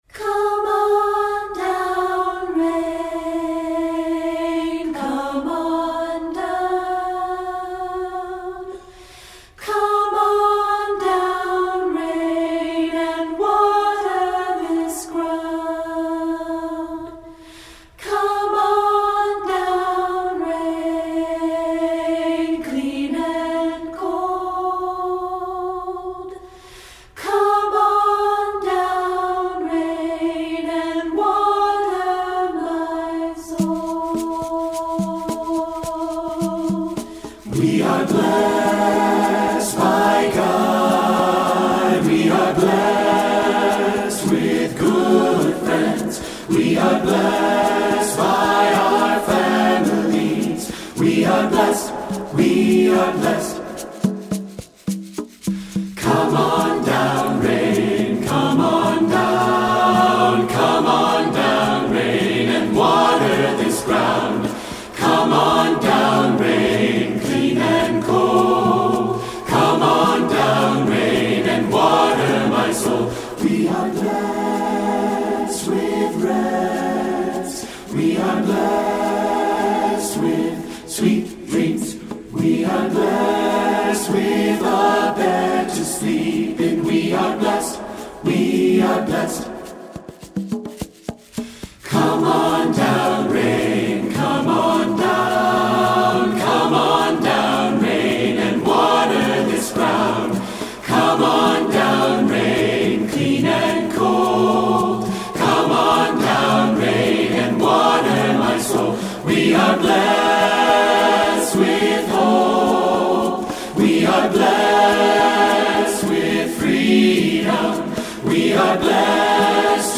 Anthems for Mixed Voices (SATB/SAB)
SATB with optional percussion